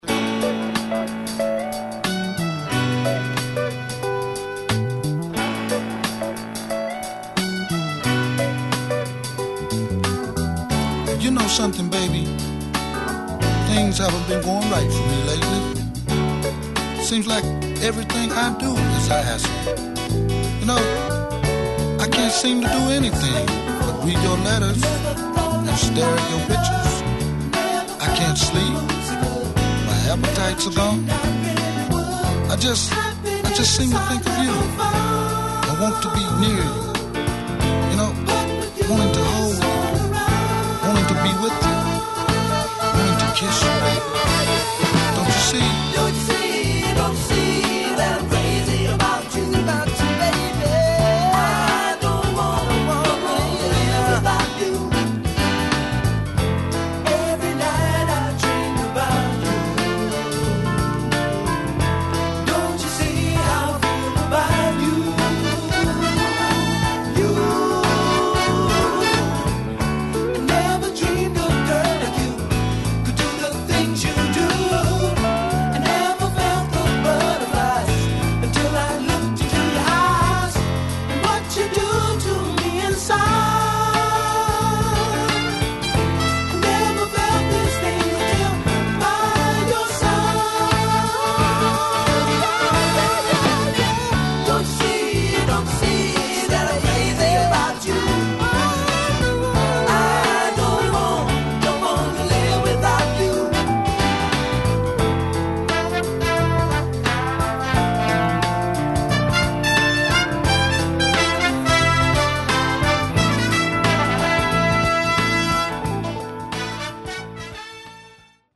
Format: 7 Inch